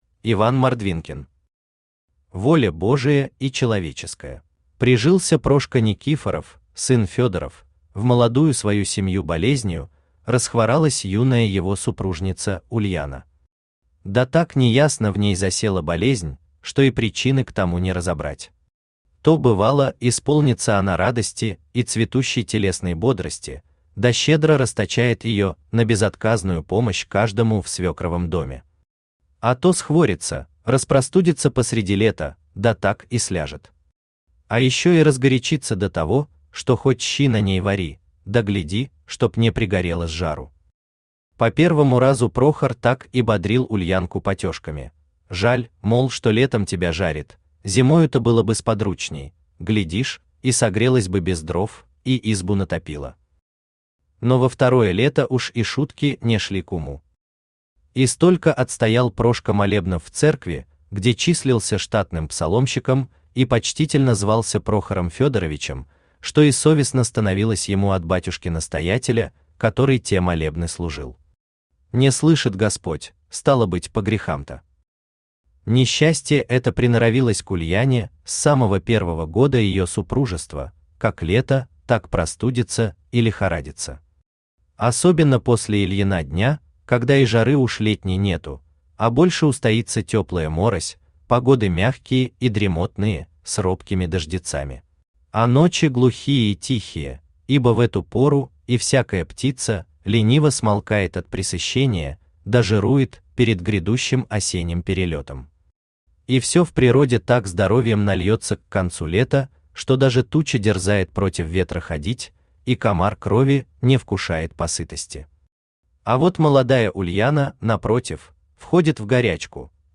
Aудиокнига Воля Божия и человеческая Автор Иван Александрович Мордвинкин Читает аудиокнигу Авточтец ЛитРес. Прослушать и бесплатно скачать фрагмент аудиокниги